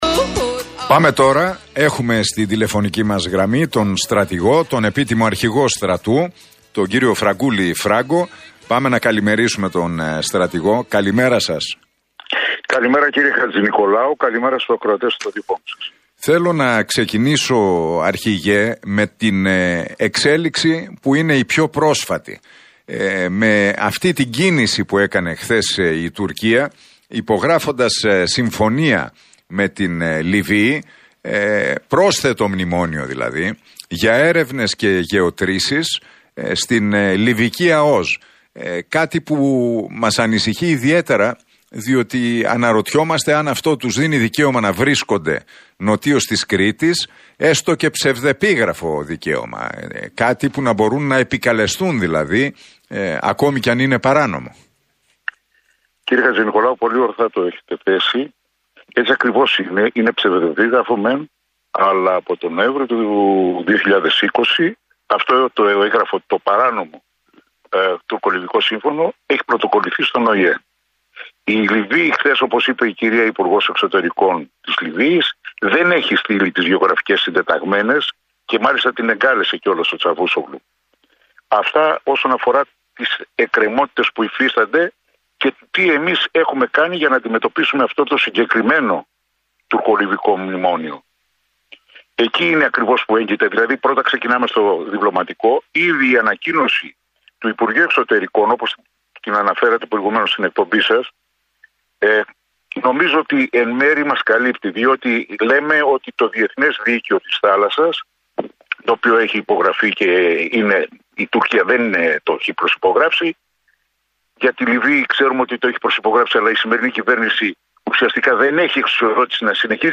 Ο επίτιμος αρχηγός ΓΕΣ, Φραγκούλης Φράγκος, μίλησε στον Realfm 97,8 και στην εκπομπή του Νίκου Χατζηνικολάου για τις τουρκικές προκλήσεις.